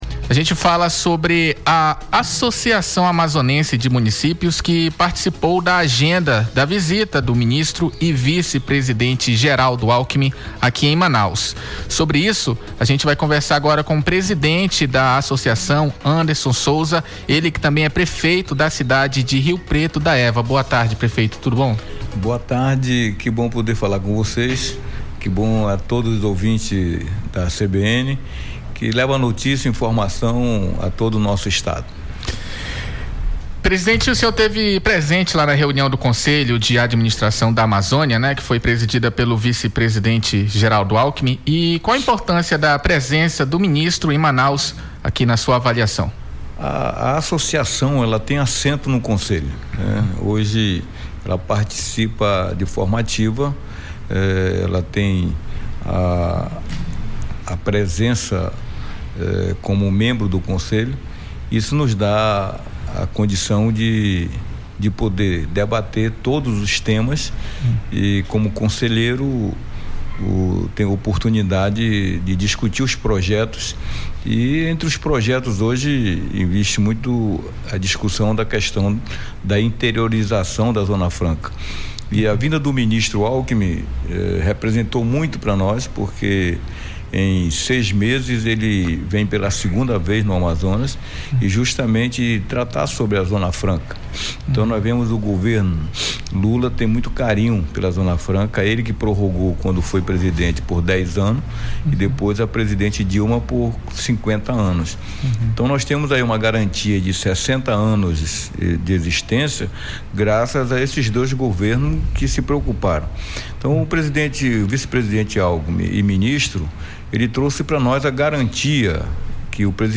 O presidente da Associação dos Municípios do Amazonas, Anderson Sousa, disse em entrevista para o CBN Tarde de Notícias, que a visita do ministro e vice-presidente Geraldo Alckmin reforçou a segurança e a importância que a Zona Franca de Manaus tem na econômia nacional.
entrevista-tarde.mp3